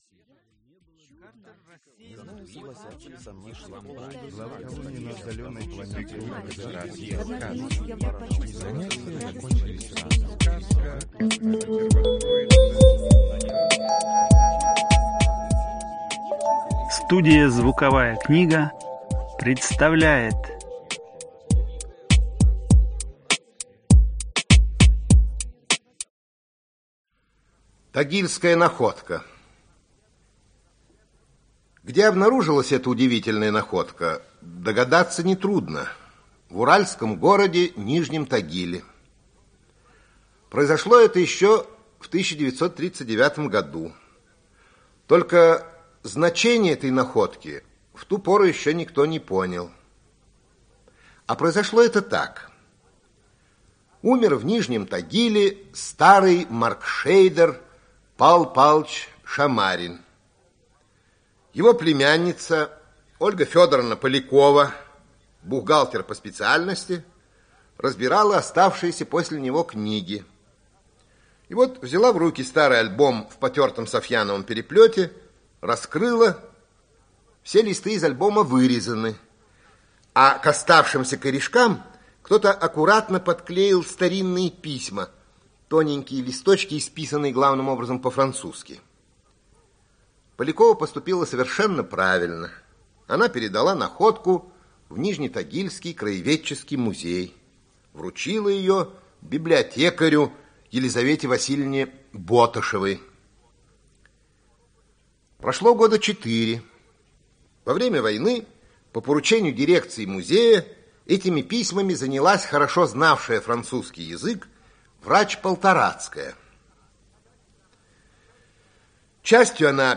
Аудиокнига Тагильская находка | Библиотека аудиокниг
Aудиокнига Тагильская находка Автор Ираклий Андроников Читает аудиокнигу Ираклий Андроников.